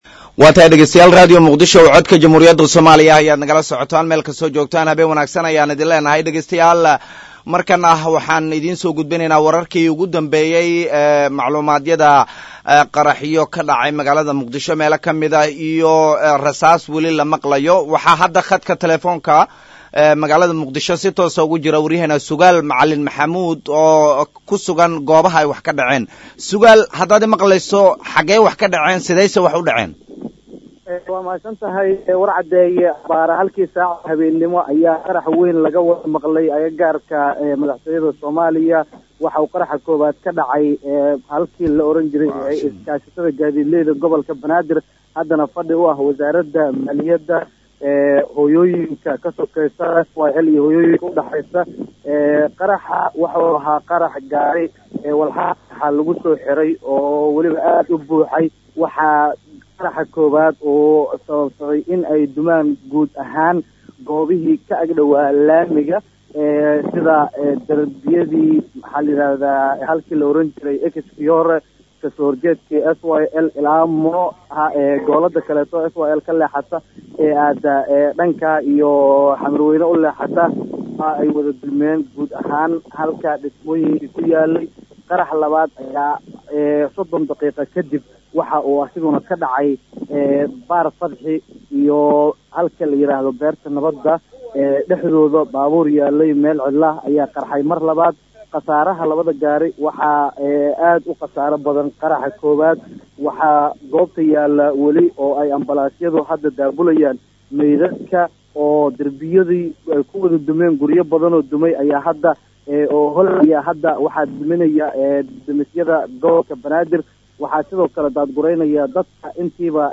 Barnamij gaar ah oo hada si toos ah uga socda Radio Muqdisho ayaan idiin soo guddbineynaa ee halkaan toos uga